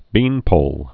(bēnpōl)